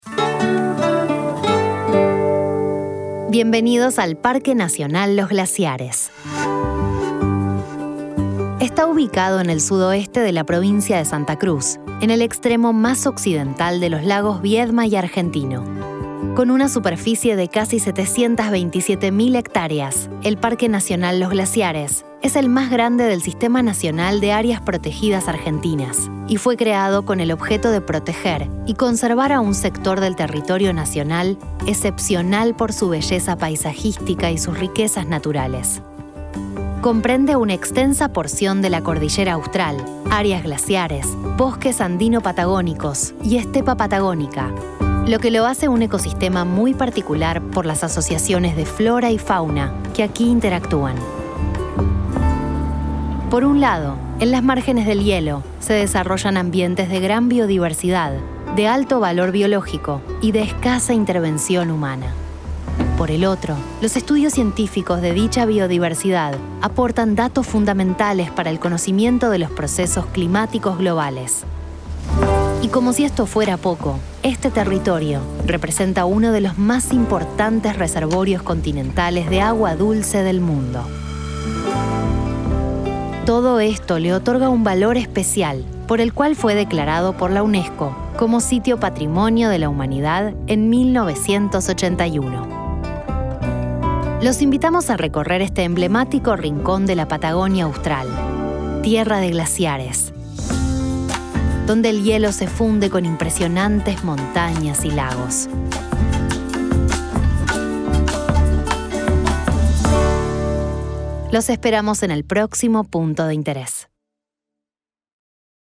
Audioguías
apn_audioguia_esp_pnlg_0_bienvenida.mp3